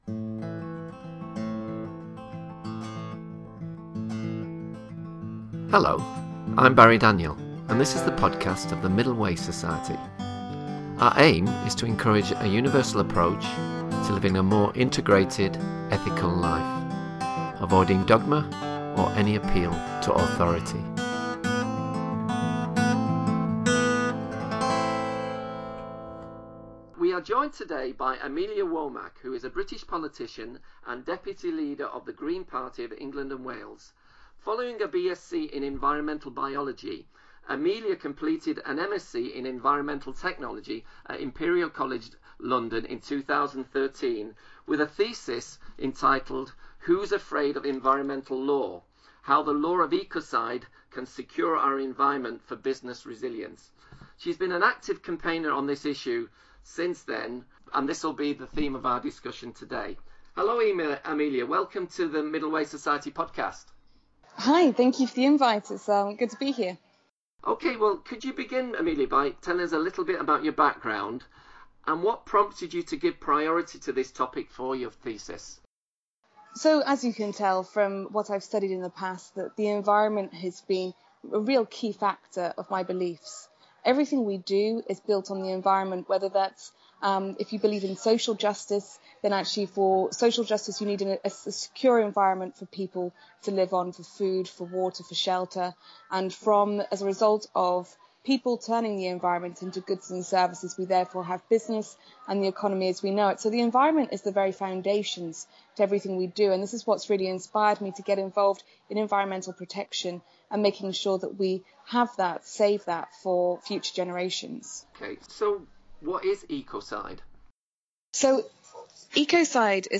We are joined today by Amelia Womack, who is a British politician and deputy leader of the Green Party of England and Wales.